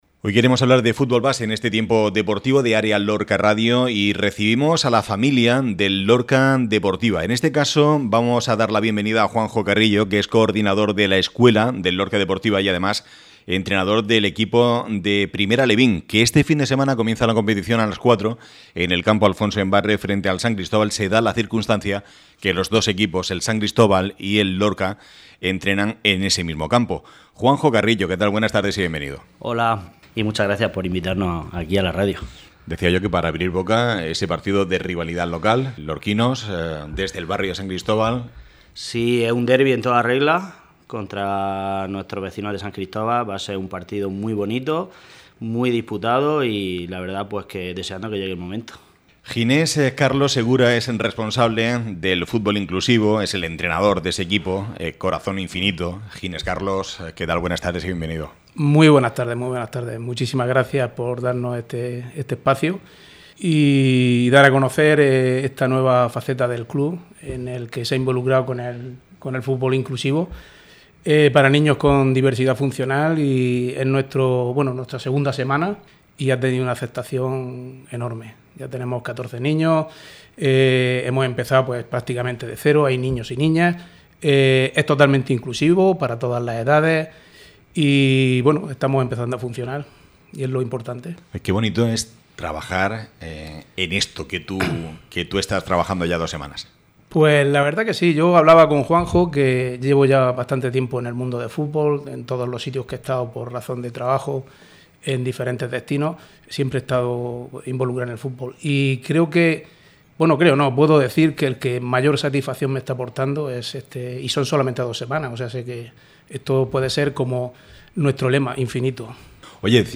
Escucha aquí la intervención en el programa deportivo de Área Lorca Radio, de los representantes del Lorca Deportiva.